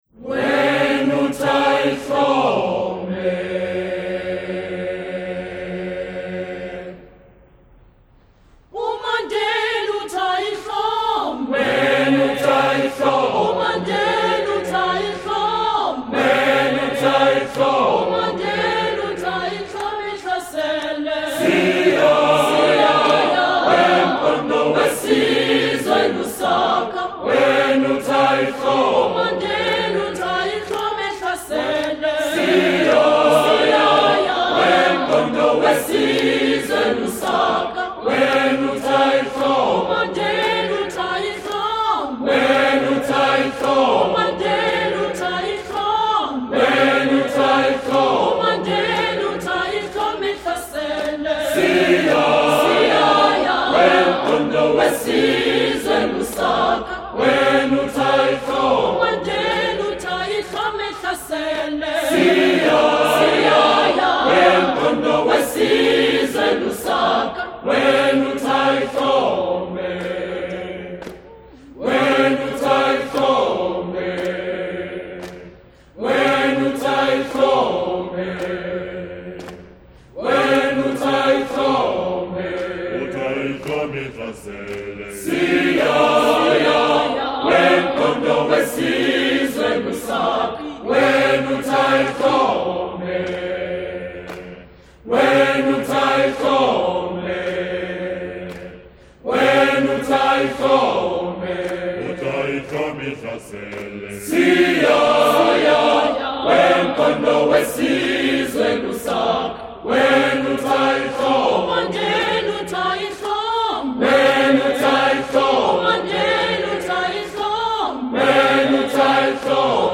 Genre-Style-Form: Folk music ; Secular ; Call and response
Mood of the piece: sustained ; agressive ; proud
Type of Choir: SATB  (4 mixed voices )
Tonality: G major